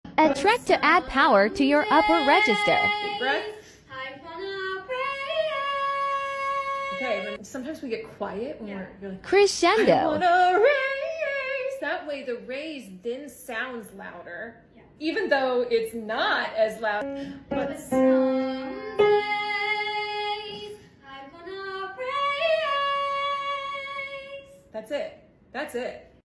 Love a good crescendo to add power to your singing voice!